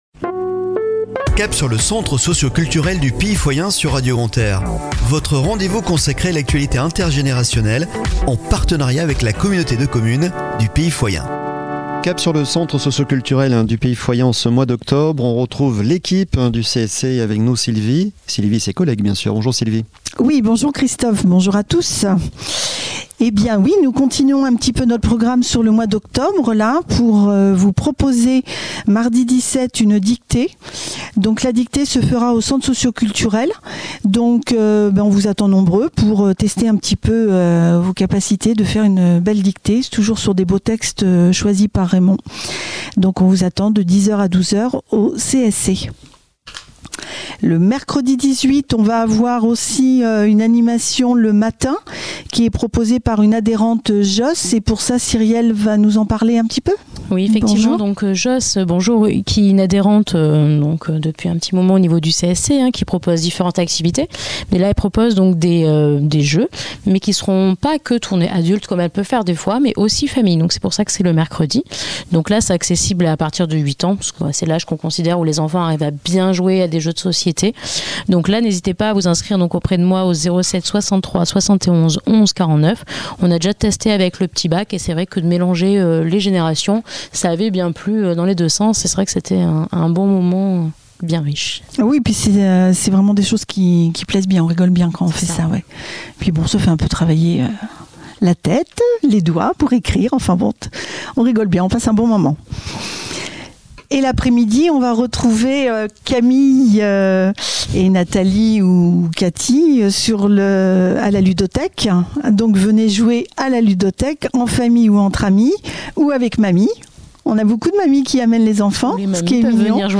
Chronique de la semaine du 09 au 15 Octobre 2023 !